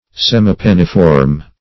Search Result for " semipenniform" : The Collaborative International Dictionary of English v.0.48: Semipenniform \Sem`i*pen"ni*form\ (s[e^]m`[i^]*p[e^]n"n[i^]*f[^o]rm), a. (Anat.)
semipenniform.mp3